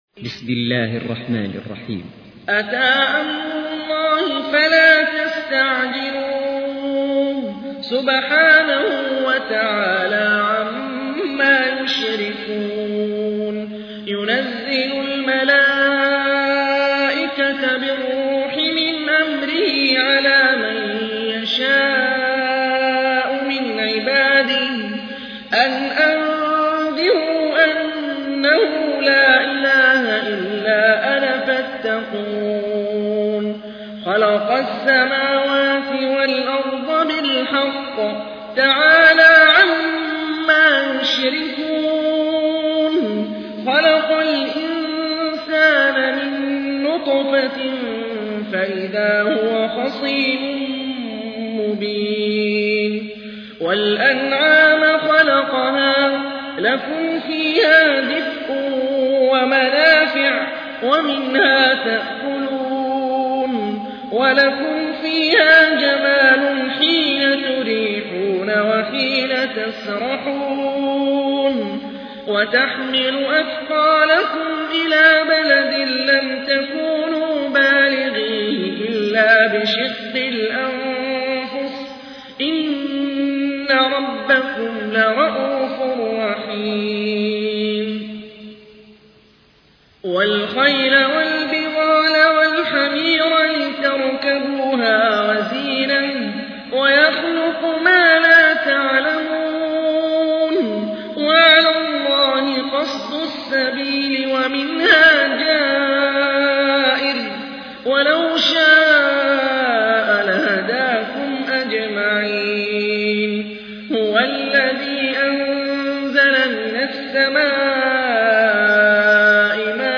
تحميل : 16. سورة النحل / القارئ هاني الرفاعي / القرآن الكريم / موقع يا حسين